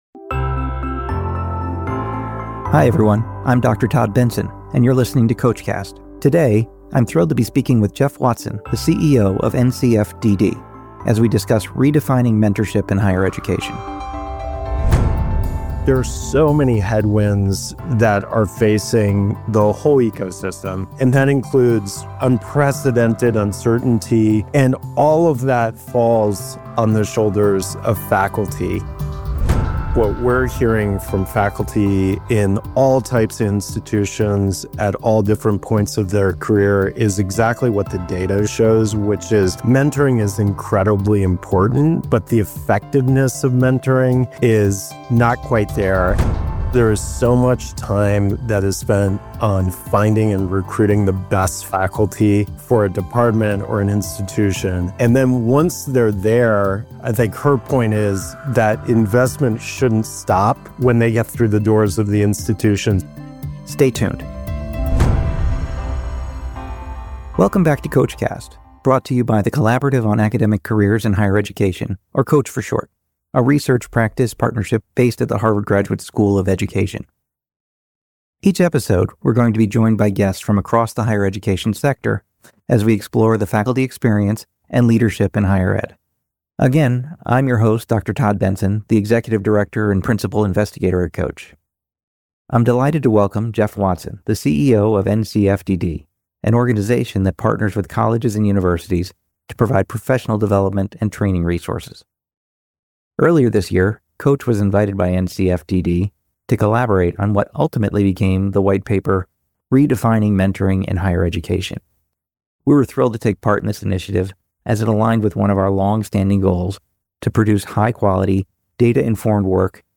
for a deeper conversation on the ideas behind the paper—and what they mean for institutional leaders today.